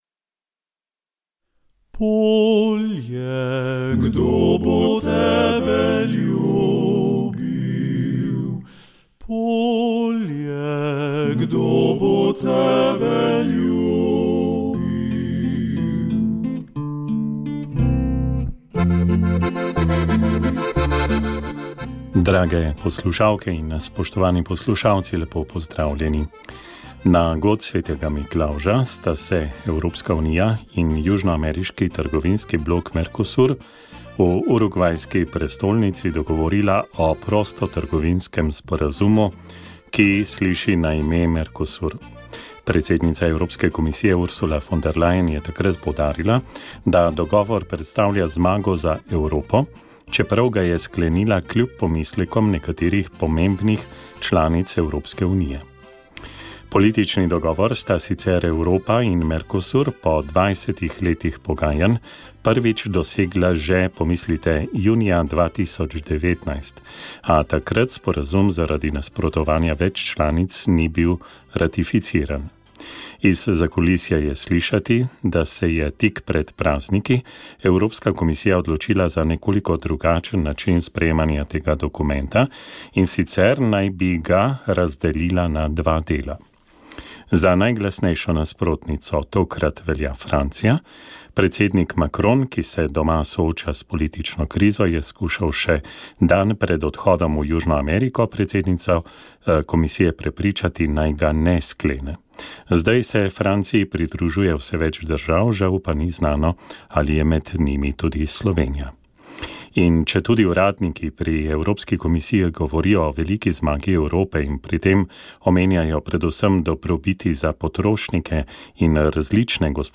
Blagor tistim, ki verujejo: Duhovni nagovor za 4. adventno nedeljo